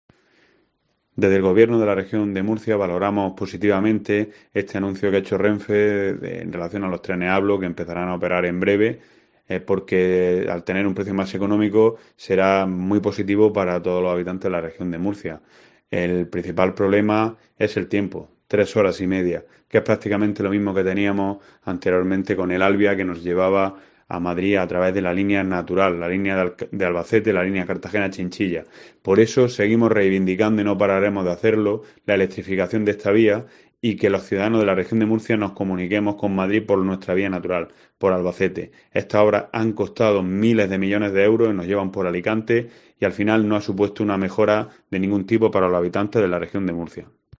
José Manuel Pancorbo, consejero de Fomento e Infraestructuras